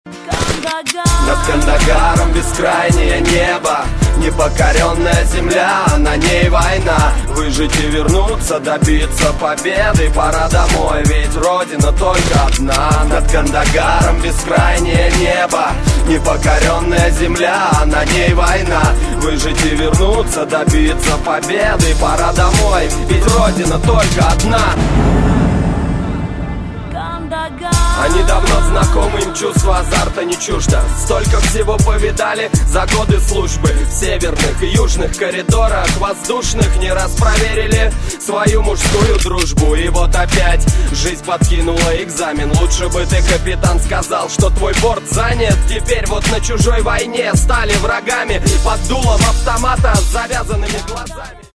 • Качество: 128, Stereo
русский рэп
патриотические